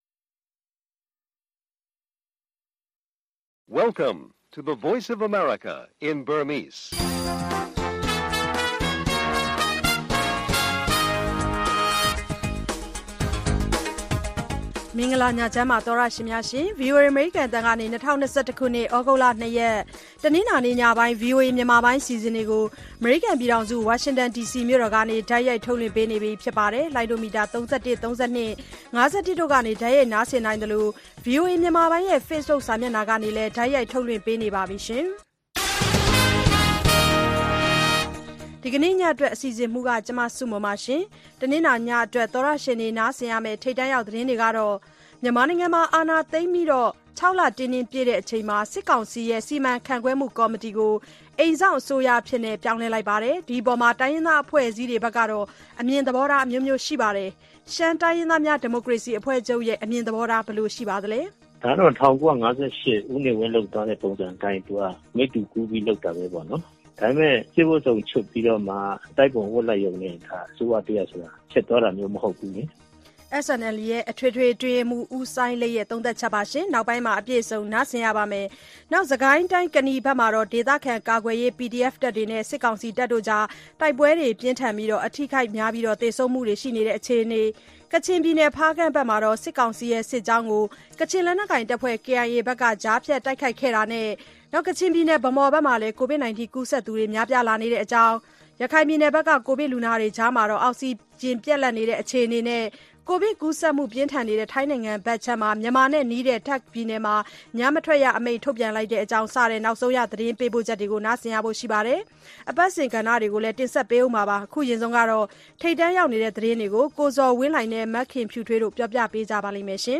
VOA ရေဒီယိုညပိုင်း ၉း၀၀-၁၀း၀၀ တိုက်ရိုက်ထုတ်လွှင့်မှု(သြဂုတ် ၂၊ ၂၀၂၁)